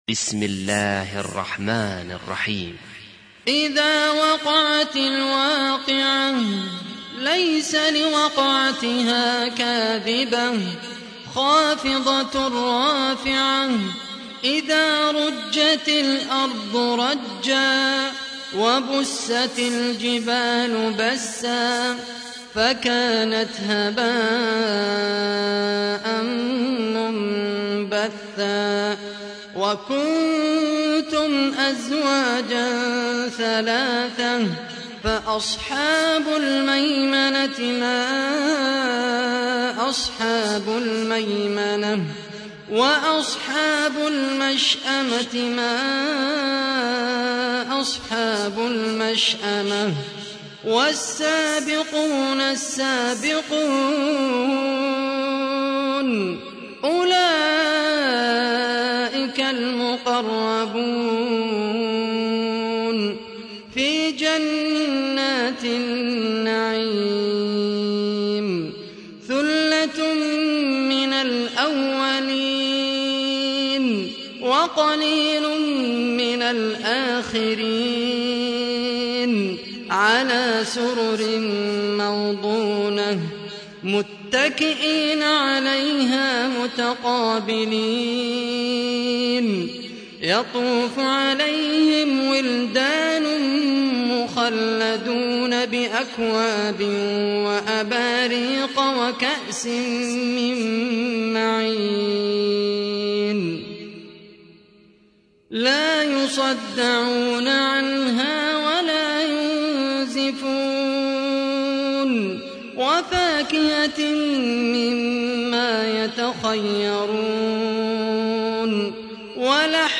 تحميل : 56. سورة الواقعة / القارئ خالد القحطاني / القرآن الكريم / موقع يا حسين